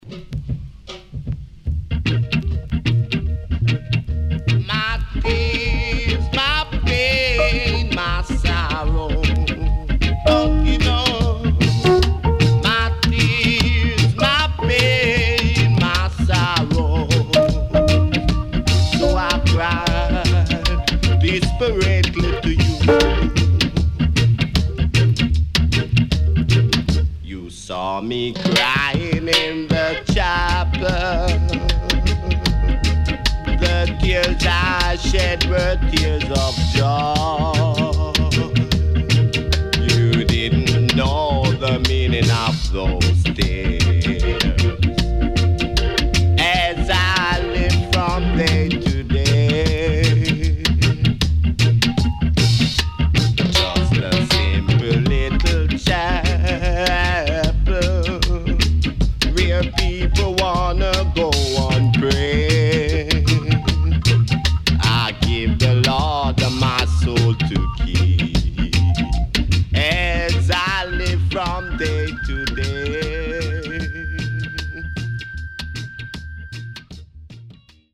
Melodica Inst.Good Condition